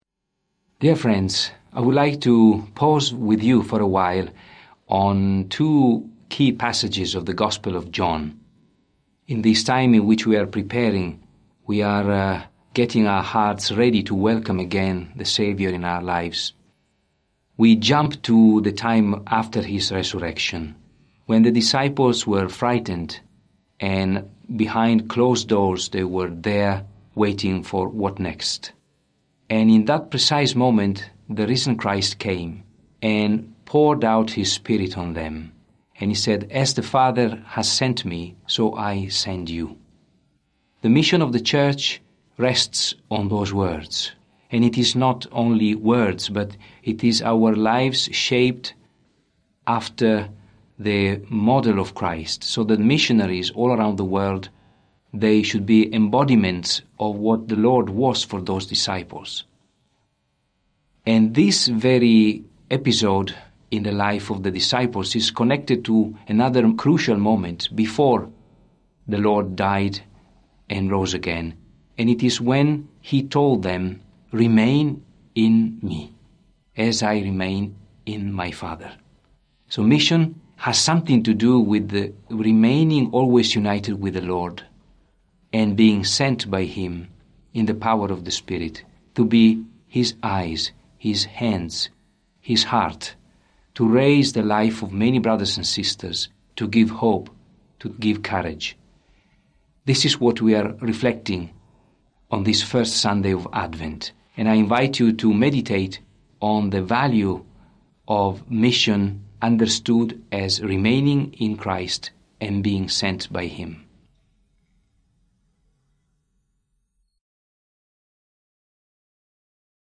Archdiocese of Brisbane First Sunday of Advent - Two-Minute Homily: Cardinal Giorgio Marengo Nov 27 2024 | 00:02:01 1x 00:00 / 00:02:01 Subscribe Share RSS Feed Share Link Embed